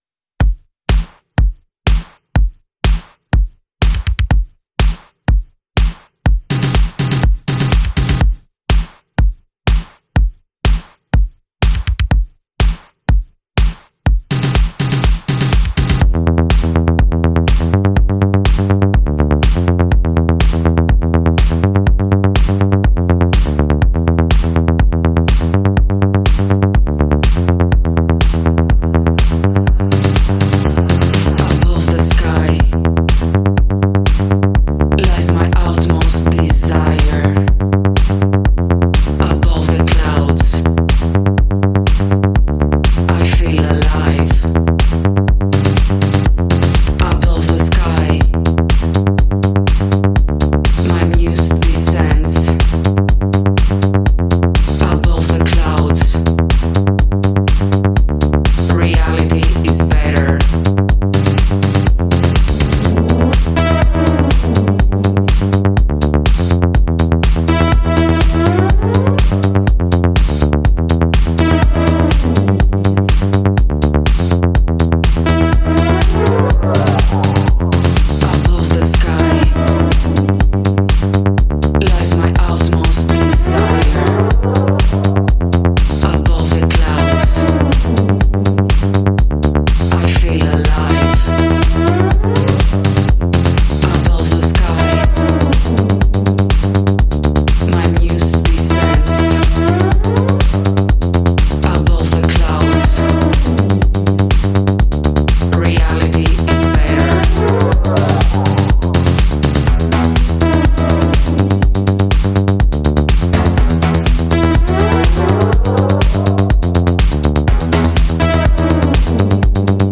trippy